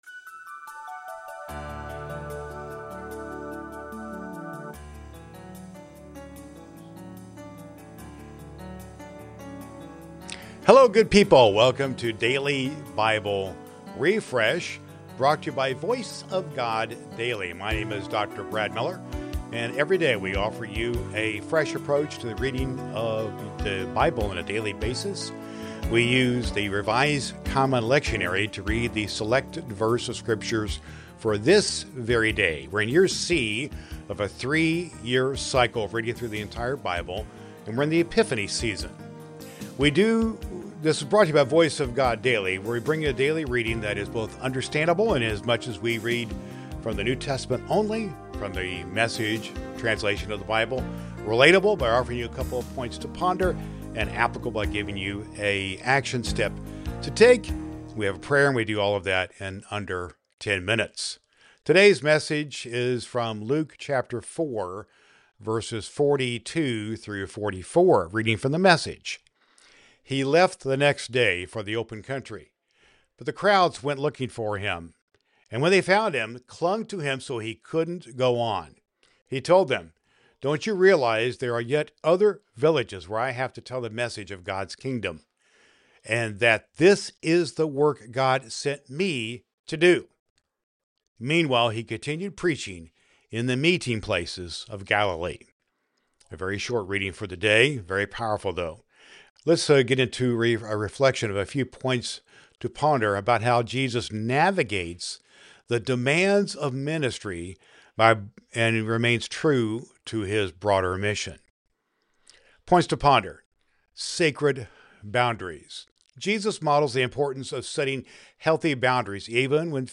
• Understandable: A reading from the New Testament (usually the Gospel) selected from the Revised Common Lectionary using "The Message" translation.
• A prayer for your day.
• The podcast concludes with a prayer, seeking guidance in balancing personal well-being and community service.